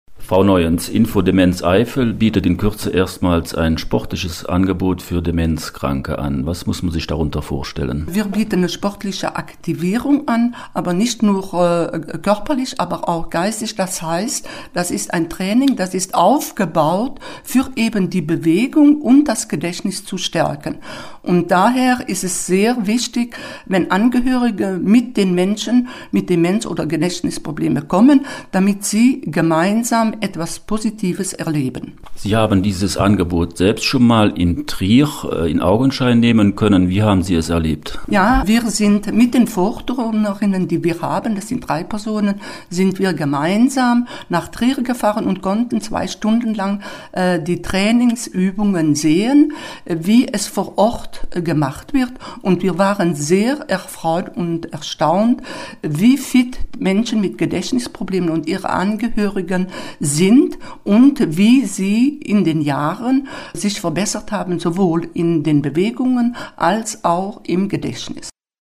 hat sich darüber
unterhalten